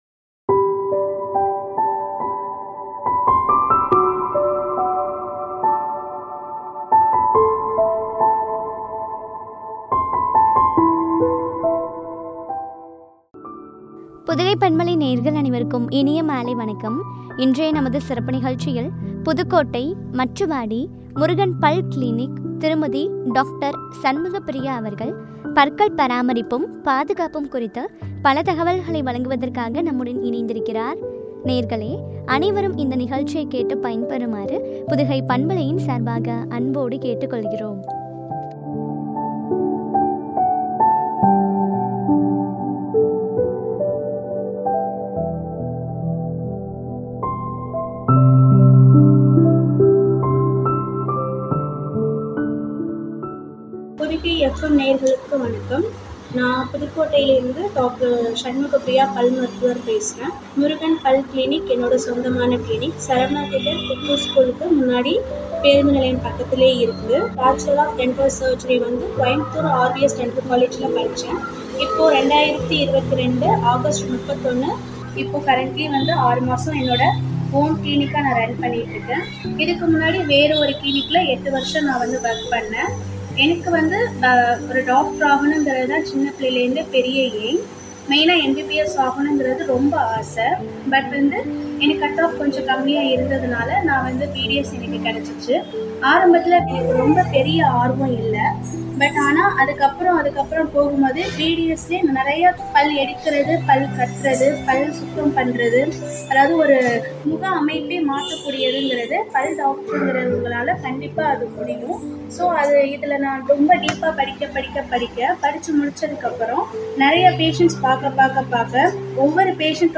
பாதுகாப்பும் பற்றிய உரையாடல்.